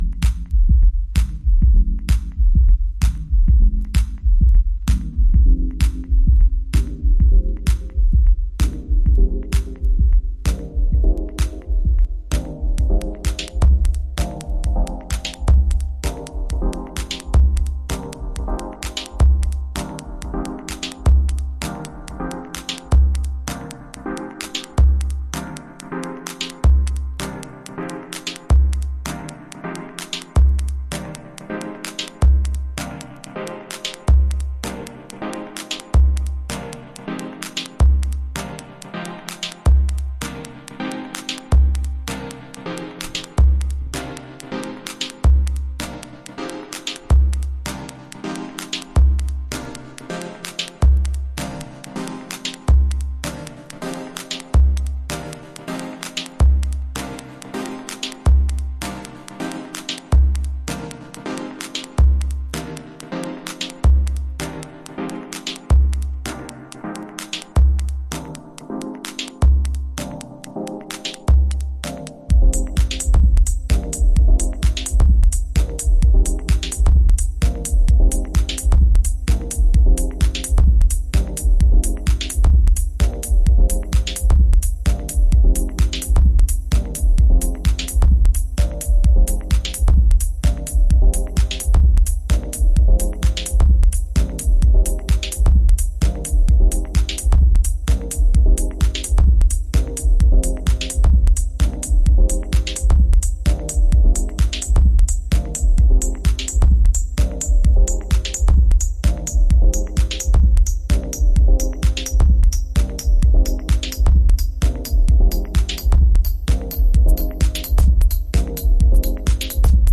クラシカルテクノ/ミニマルのオイシイところを凝縮したようなアナログ志向のトラック。